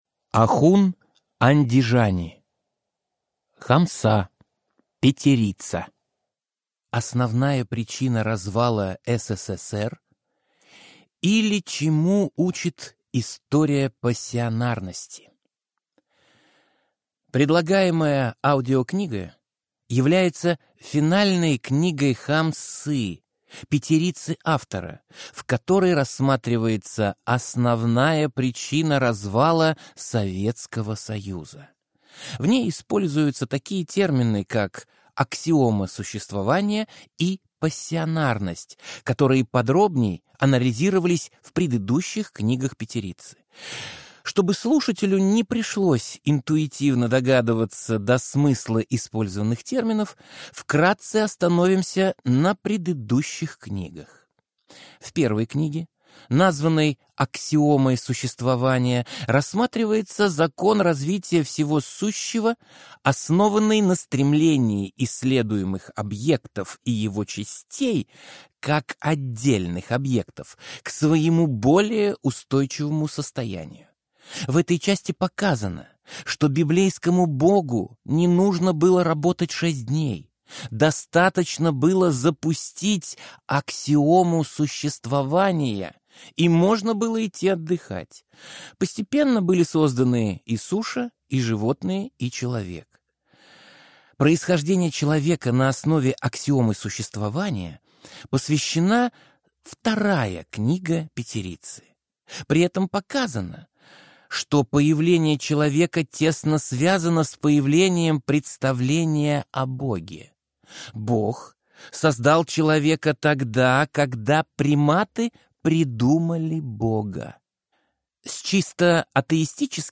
Аудиокнига Основная причина развала СССР | Библиотека аудиокниг
Прослушать и бесплатно скачать фрагмент аудиокниги